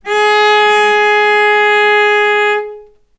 healing-soundscapes/Sound Banks/HSS_OP_Pack/Strings/cello/ord/vc-G#4-ff.AIF at 48f255e0b41e8171d9280be2389d1ef0a439d660
vc-G#4-ff.AIF